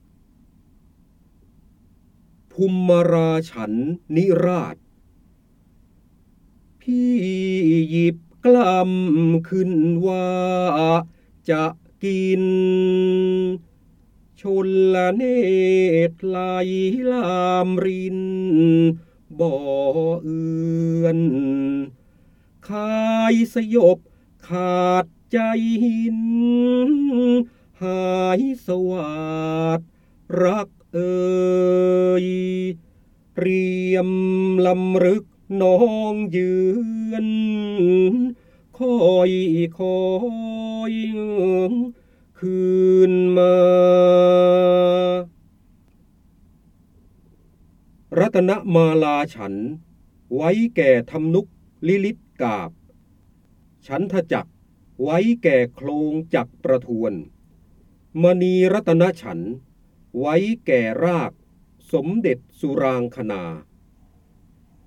เสียงบรรยายจากหนังสือ จินดามณี (พระโหราธิบดี) ภุมราฉันทนิราศ